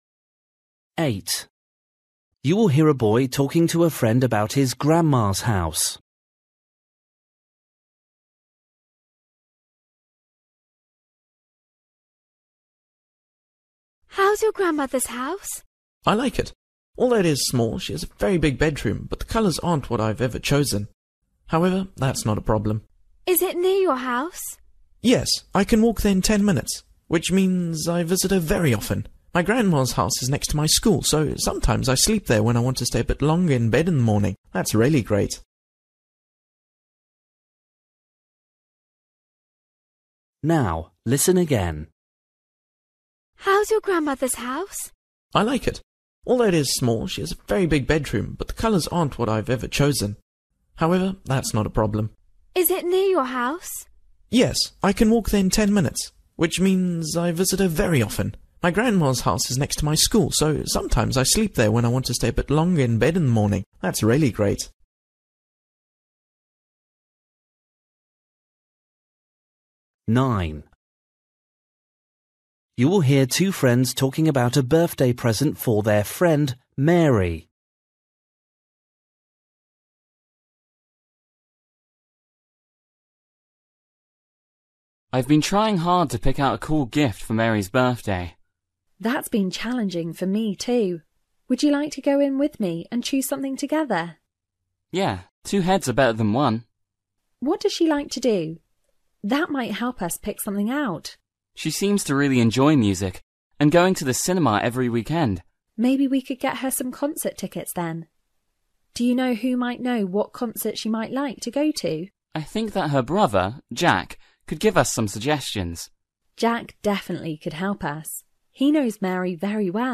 Listening: everyday short conversations
8   You will hear a boy talking to a friend about his grandma’s house. What does the boy like most about it?
10   You will hear two friends talking about a concert. What do they agree on?